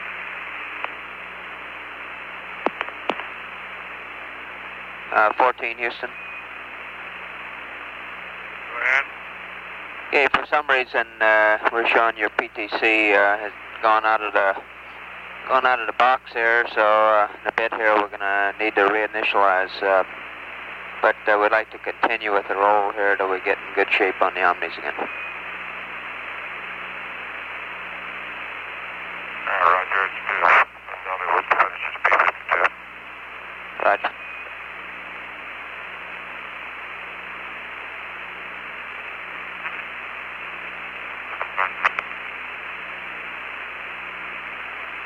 PAO loop.